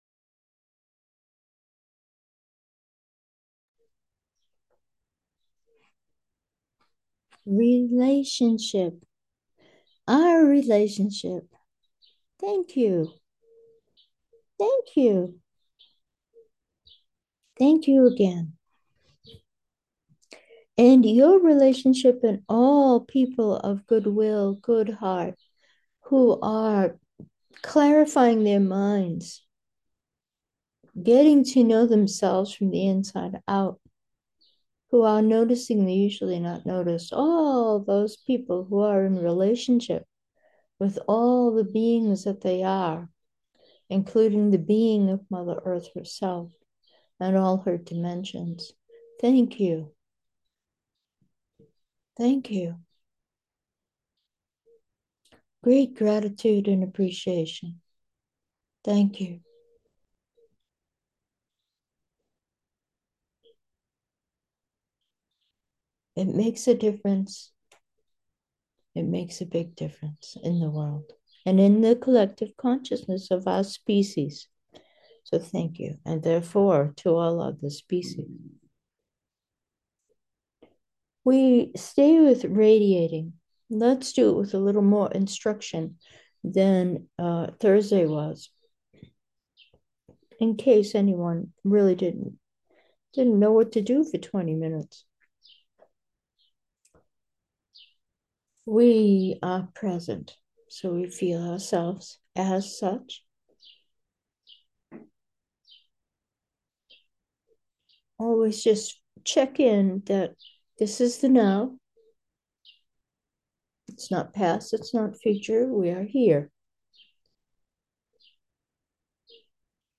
Meditation: radiating, with instructions